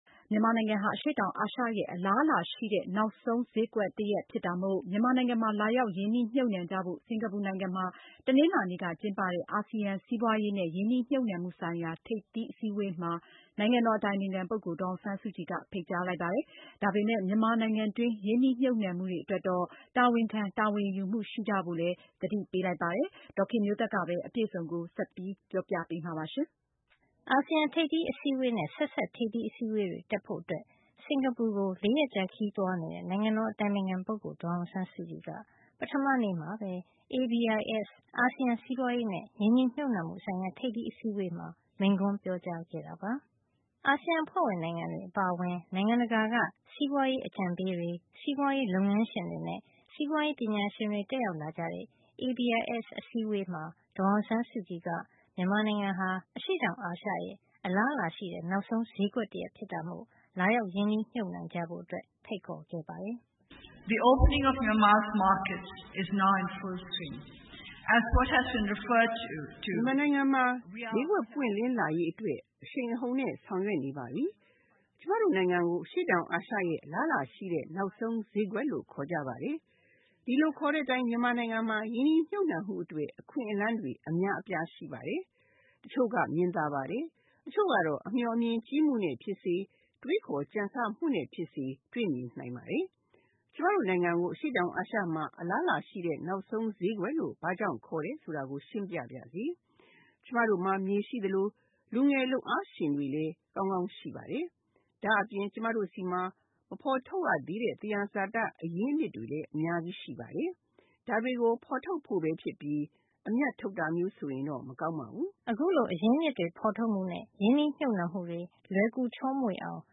အာဆီယံ ထိပ်သီးအစည်းအဝေးနှင့် ဆက်စပ်ထိပ်သီး အစည်းအဝေးတွေ တက်ရောက်ဖို့ စင်္ကာပူနိုင်ငံကို ၄ ရက်ကြာခရီးသွားရောက်နေတဲ့ နိုင်ငံတော်အတိုင်ပင်ခံပုဂ္ဂိုလ် ဒေါ်အောင်ဆန်းစုကြည်က ပထမနေ့မှာပဲ ABIS အာဆီယံ စီးပွါးရေးနဲ့ ရင်းနှီးမြှုပ်နှံမှုဆိုင်ရာ ထိပ်သီး အစည်းအဝေးမှာ မိန့်ခွန်းပြောကြားခဲ့တာပါ။ အာဆီယံအဖွဲ့ဝင်နိုင်ငံများအပါအဝင် နိုင်ငံတကာက စီးပွါးရေးအကြံပေးတွေ၊ စီးပွါးရေး လုပ်ငန်းရှင်တွေနဲ့ စီးပွါးရေးပညာရှင်တွေ တက်ရောက်လာကြတဲ့ ABIS အစည်းအဝေးမှာ ဒေါ်အောင်ဆန်းစုကြည်က မြန်မာနိုင်ငံဟာ အရှေ့တောင်အာရှရဲ့ အလားအလာရှိတဲ့ နောက်ဆုံးဈေးကွက်တရပ်ဖြစ်တာမို့ လာရောက် ရင်းနှီးမြှုပ်နှံကြဖို့ ဖိတ်ခေါ်ခဲ့ပါတယ်။